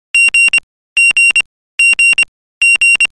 Morse code online (Alphabet) - Letter G-G
• Category: Morse Code